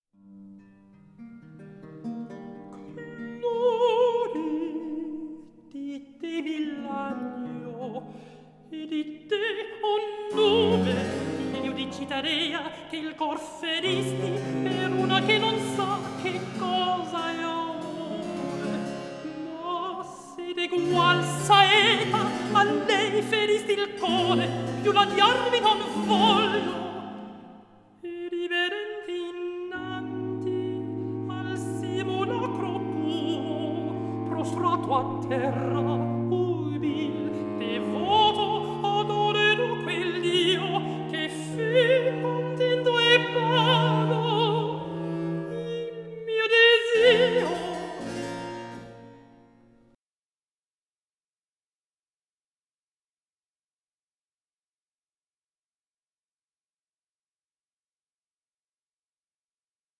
countertenor
one of today’s most renowned early music ensembles.